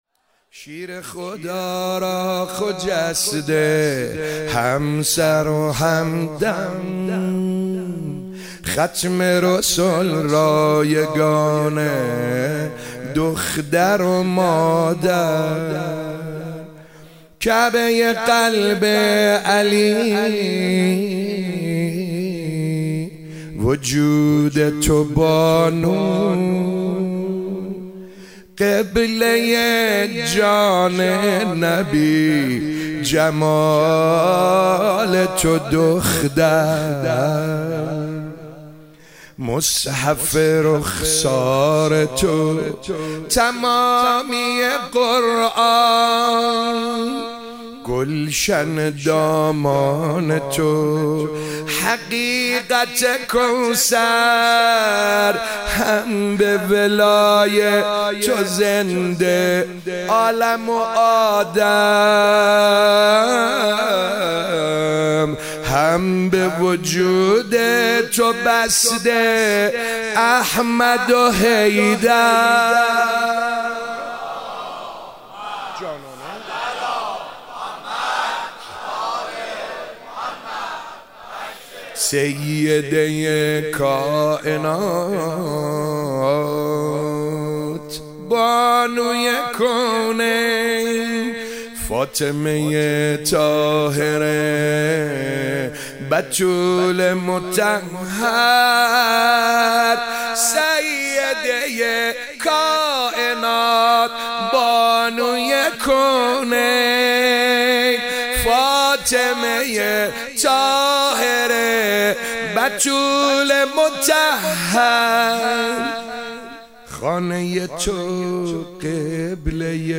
فاطمیه 97 - روایت اول - شب سوم - روضه - شیر خدا را همسر و همدم